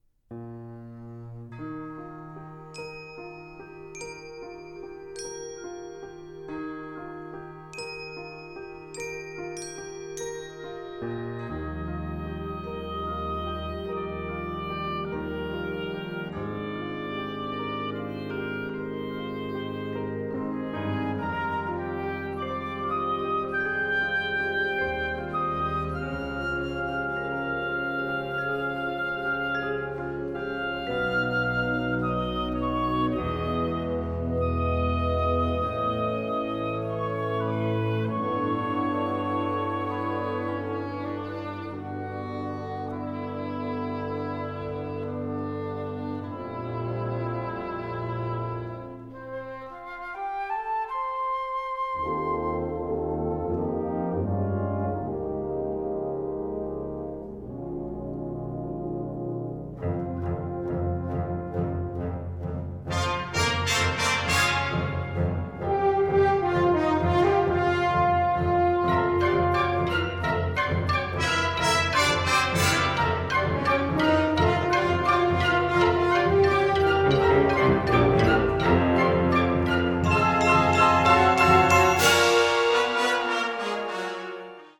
Kategorie Blasorchester/HaFaBra
Unterkategorie Konzertmusik
Besetzung Ha (Blasorchester)